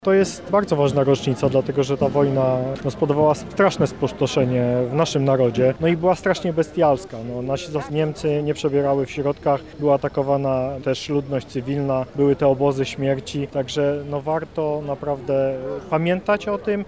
Z tej okazji na Placu litewskim odbyły się uroczyste obchody 79 rocznicy zakończenia II wojny światowej i zwycięstwa nad hitlerowskimi Niemcami.
Andrzej Maj – mówi wicewojewoda lubelski Andrzej Maj